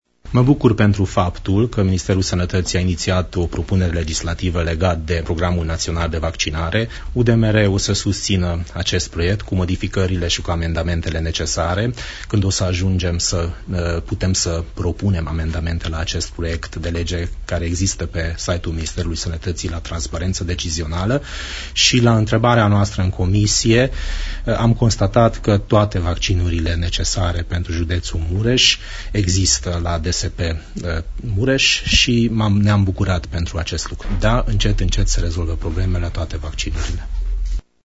Asta a declarat deputatul UDMR de Mureș, Vass Levente, astăzi într-o conferință de presă.